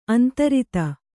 ♪ antarita